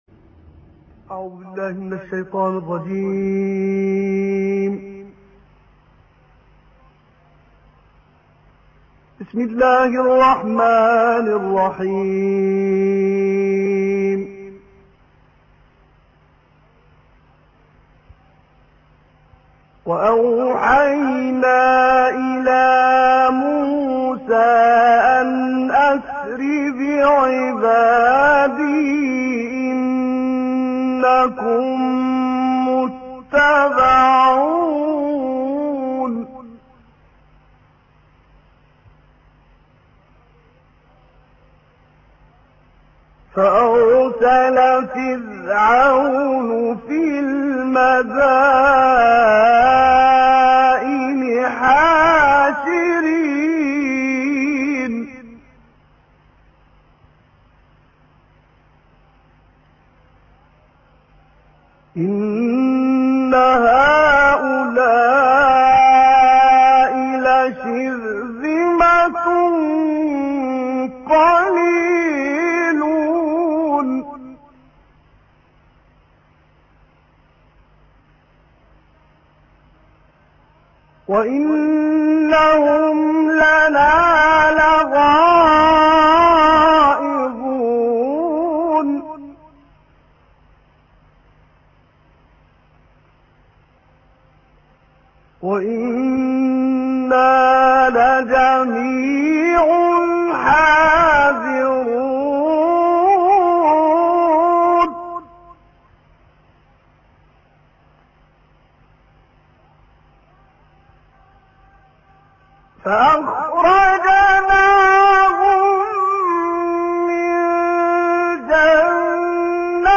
باقة من التلاوات الخاشعة والنادرة للقارئ محمد عبد العزيز حصان 2